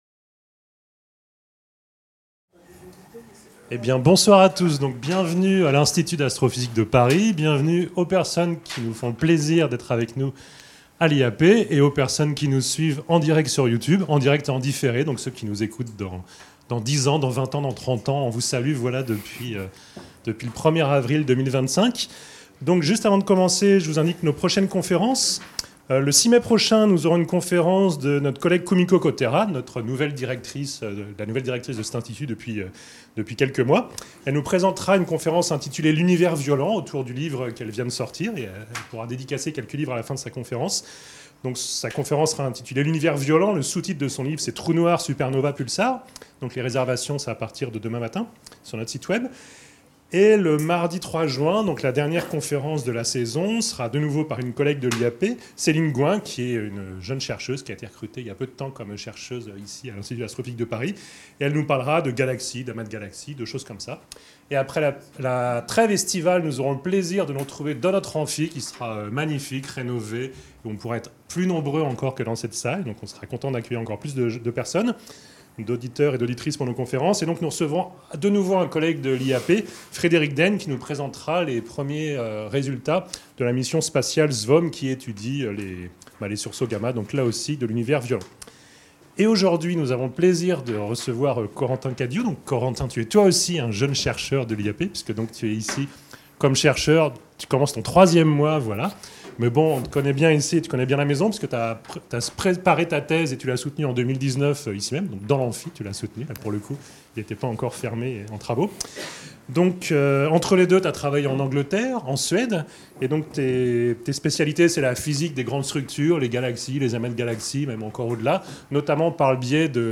Dans cette conférence, nous explorerons comment les scientifiques utilisent des simulations numériques pour reproduire l’histoire des galaxies — des premières fluctuations minuscules de matière dans l’Univers primordial jusqu’aux grandes structures cosmiques que nous voyons aujourd’hui.